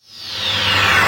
VEC3 Reverse FX
VEC3 FX Reverse 07.wav